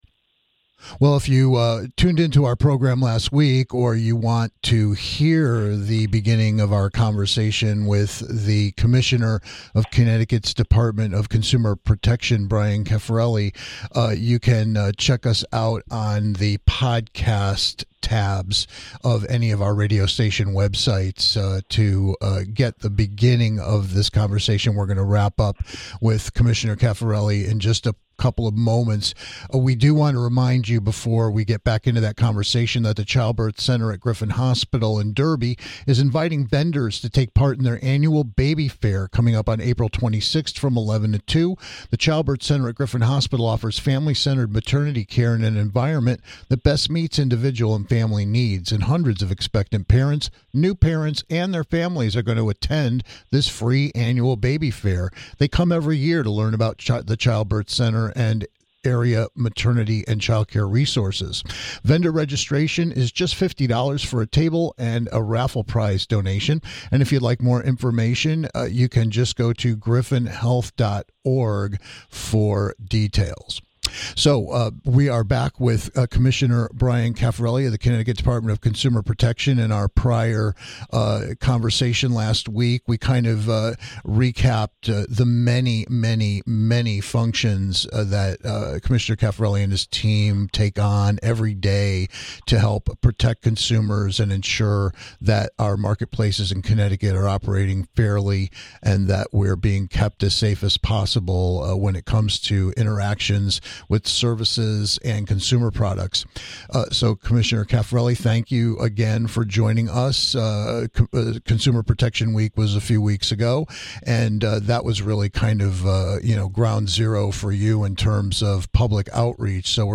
Check out some important news and solid advice in the first of a two-part chat with the Commissioner of the state's Dept. of Consumer Protection on the heels of Consumer Protection Week.